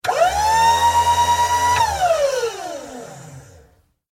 Звук мотора промышленного лифта (2)
Механизмы